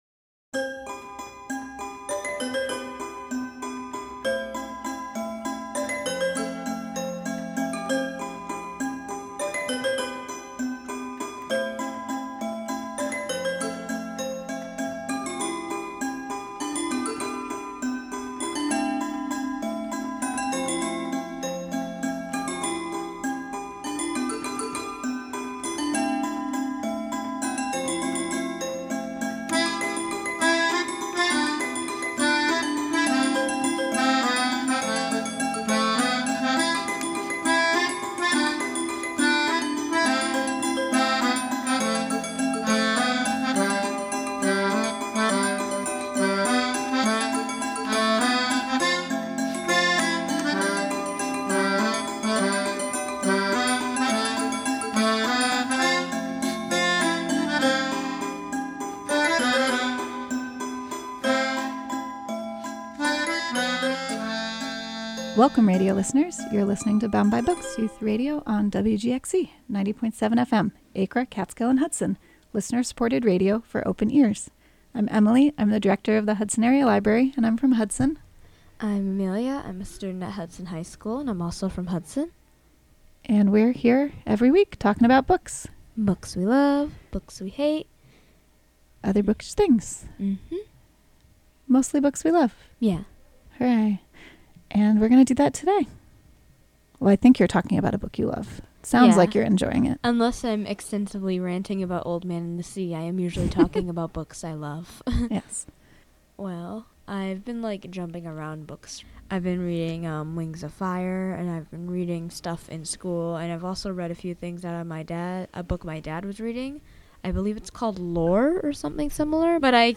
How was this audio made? Recorded Monday, April 1 at the Hudson WGXC studio.